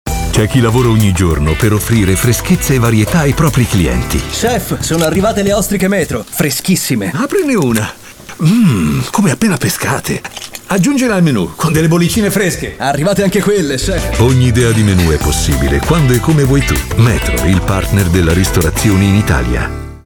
Spot Metro - Ogni menu è possibile
E’ uno degli Speaker Italiani fra i più ascoltati in televisione e in radio, particolarmente versatile l’abbiamo ascoltato sia per i toni “caldi” che per l’irresistibile freschezza e allegria.